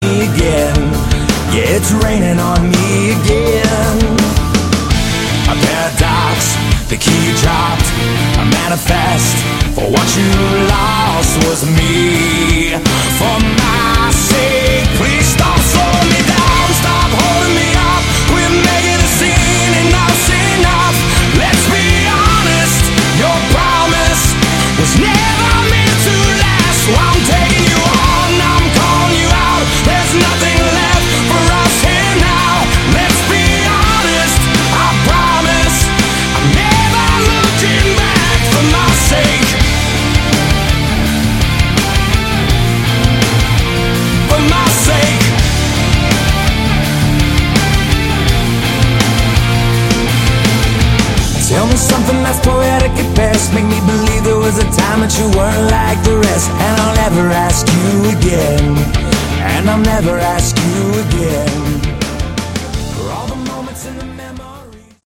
Category: Modern hard Rock
vocals
drums
guitar